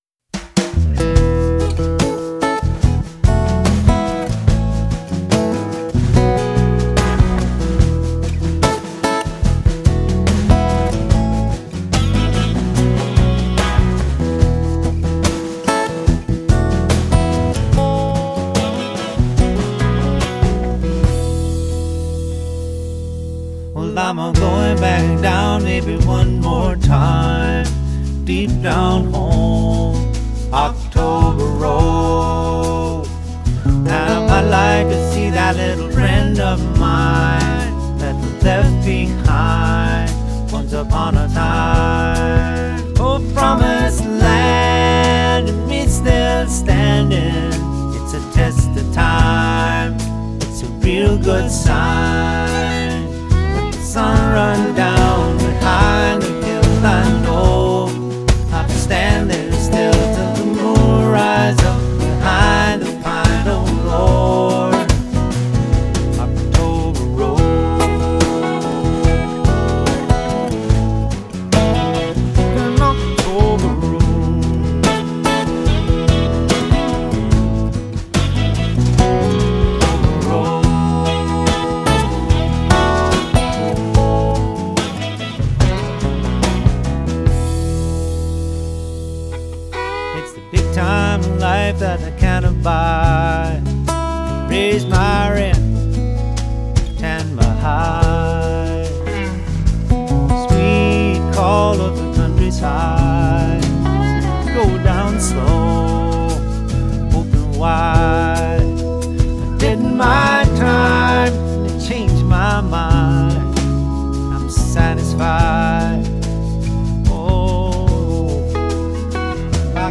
Folk Rock, Pop